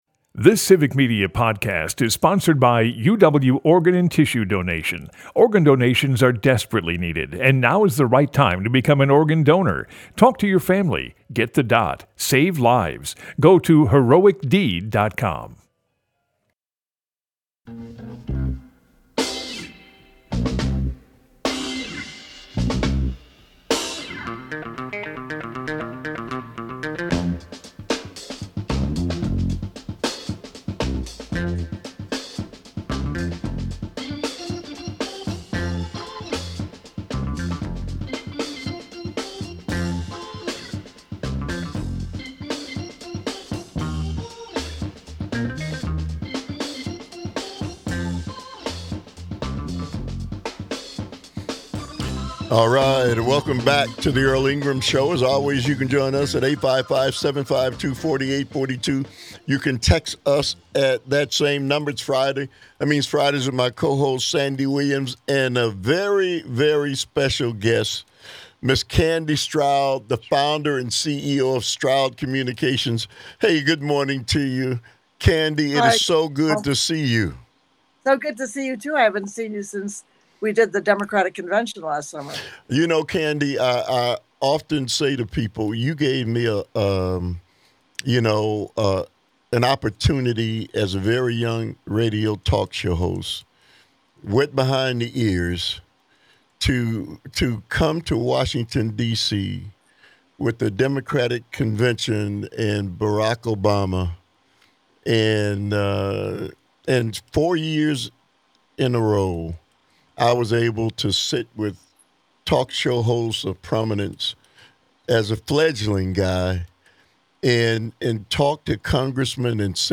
Broadcasts live 8 - 10am weekdays across Wisconsin.